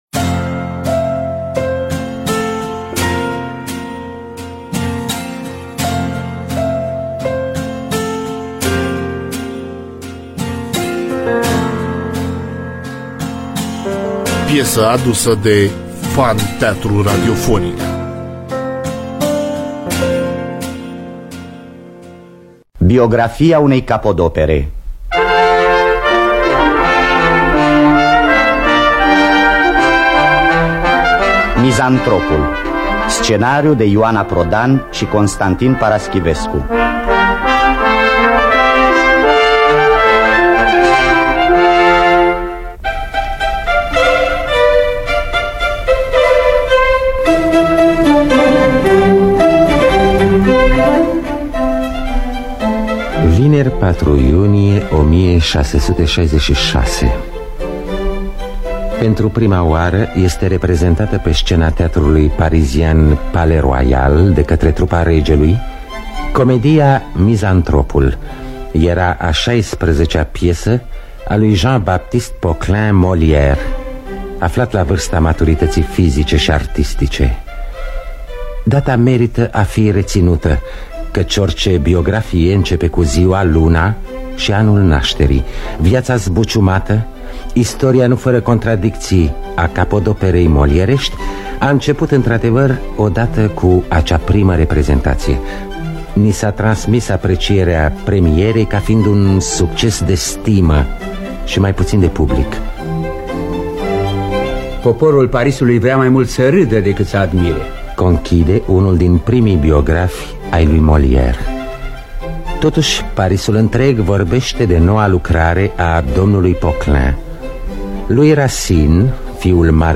Biografii, Memorii: Jean-Baptiste Poquelin de Moliere – Mizantropul (1974) – Teatru Radiofonic Online
Înregistrare din anul 1974.